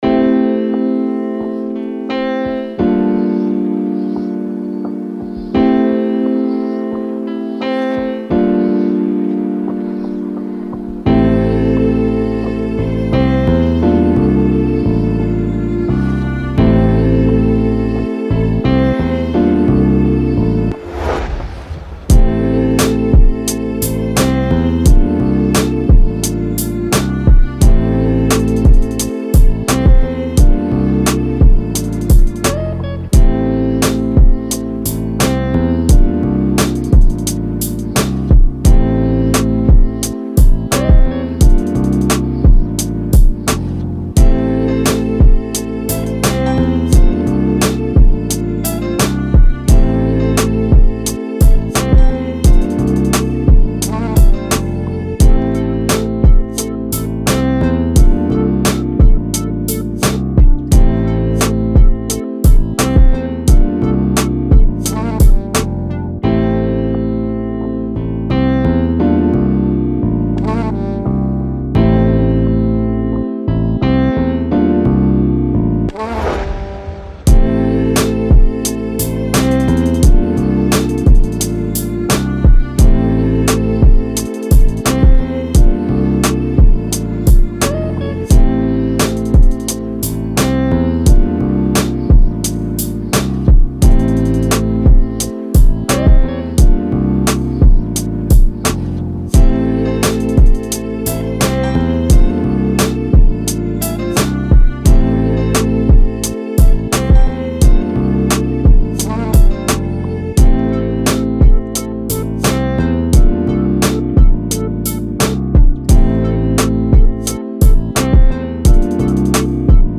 Classic, Energetic, Positive, Sexy
Drum, Heavy Bass, Piano, Strings